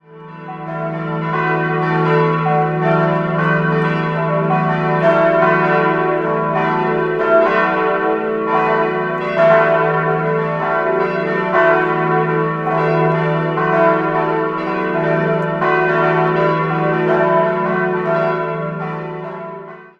Die kleine stammt vom Anfang der 1930er-Jahre und entstand bei Wolfart in Lauingen. Außerdem existiert noch eine Sterbeglocke, die aber nicht zum Hauptgeläut zählt.